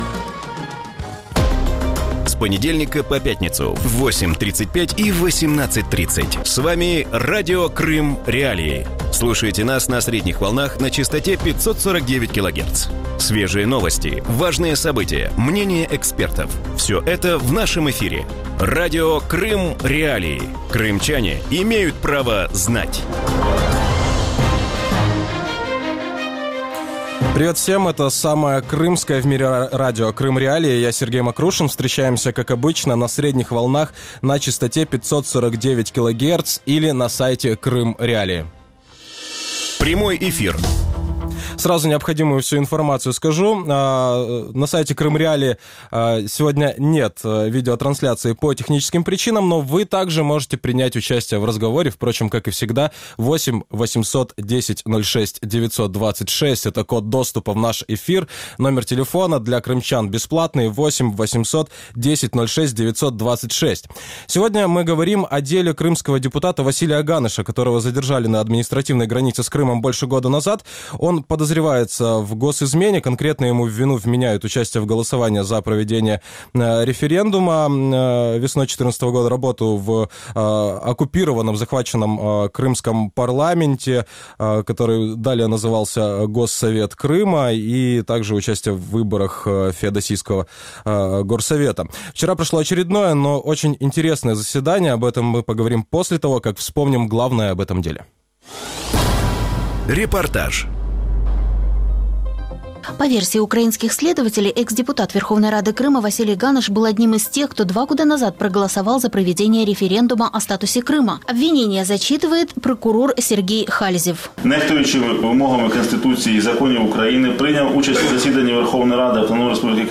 Утром в эфире Радио Крым.Реалии говорят о деле экс-депутата Верховной Рады Крыма Василия Ганыша, которого в Украине обвиняют в государственной измене. Причина – в момент аннексии он якобы участвовал в голосовании за проведение референдума о статусе полуострова.